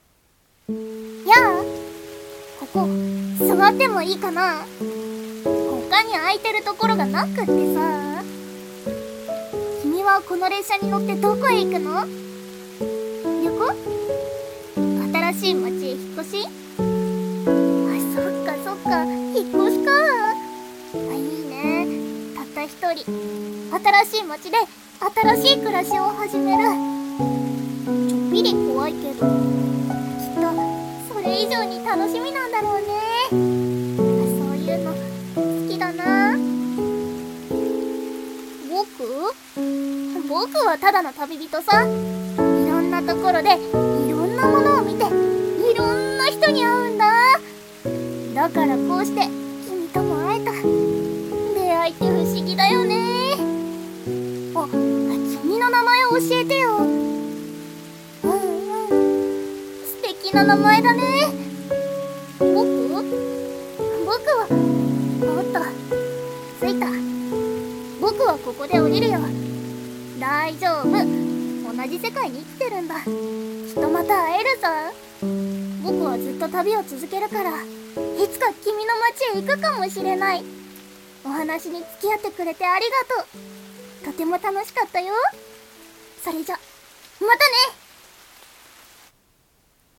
声劇「みしらぬネコ」